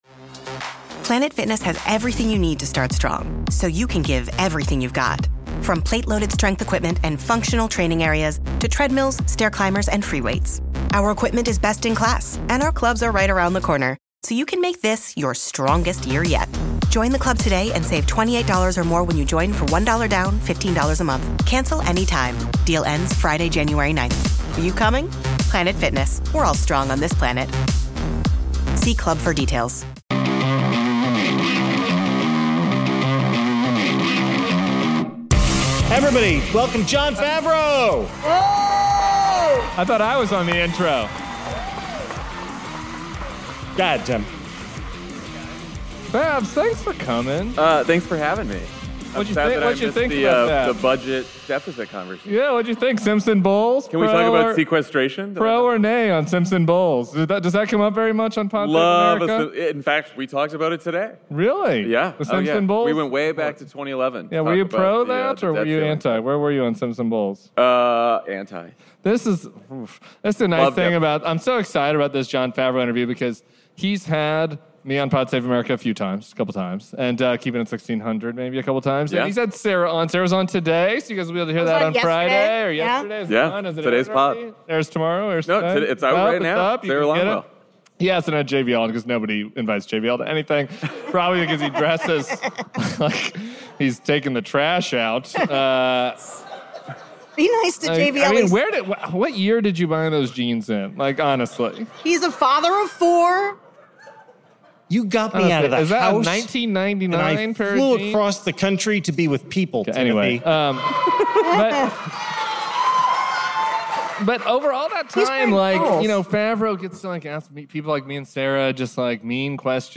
This is part two of our Jan. 19 live show from the Avalon Hollywood with special guest Jon Favreau, former Obama speechwriter and host of Pod Save American.